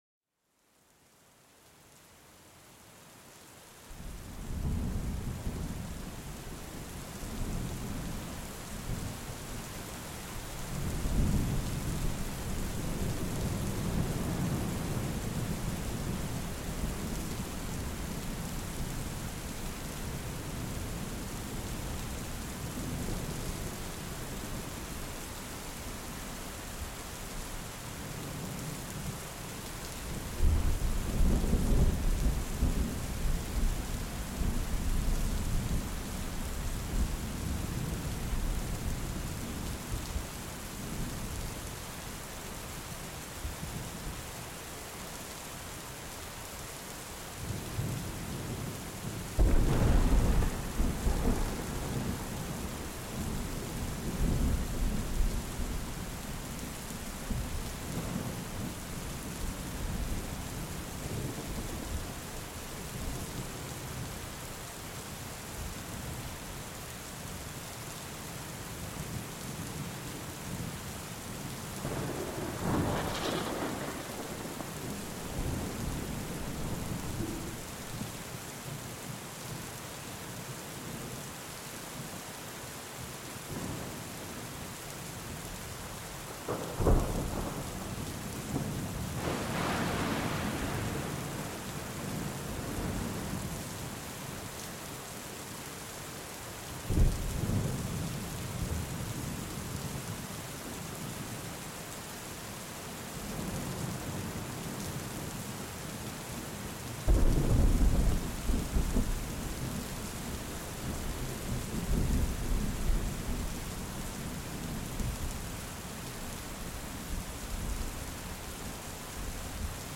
Orage et Pluie: Plénitude et Détente Profonde
Laissez-vous emporter par le son apaisant de l'orage lointain et de la pluie battante. Ces gouttes rythmiques et grondements naturels créent un cocon sonore, idéal pour apaiser l'esprit et relâcher les tensions accumulées.